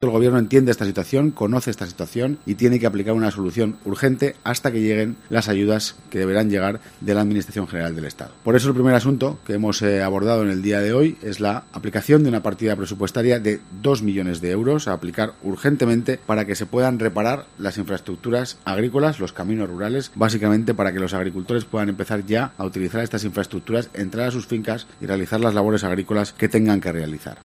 Alfonso Domínguez, portavoz del Gobierno de La Rioja